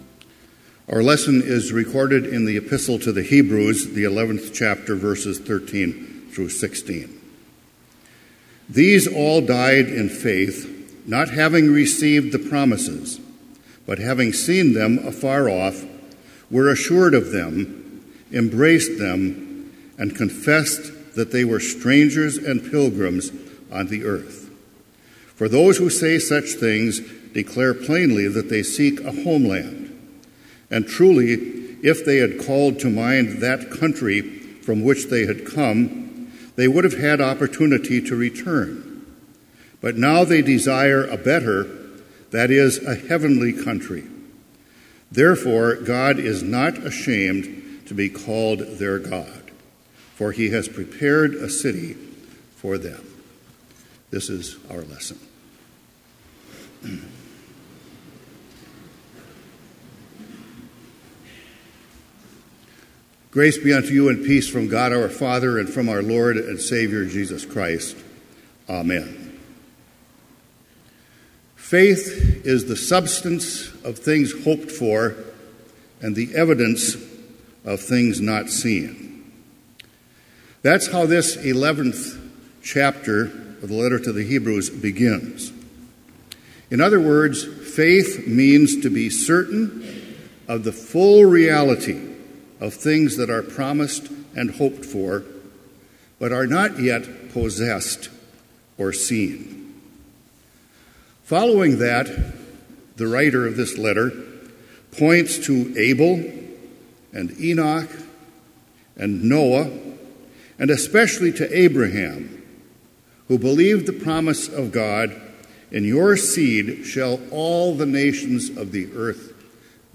Complete Service
• Prelude
• Devotion
This Chapel Service was held in Trinity Chapel at Bethany Lutheran College on Tuesday, March 14, 2017, at 10 a.m. Page and hymn numbers are from the Evangelical Lutheran Hymnary.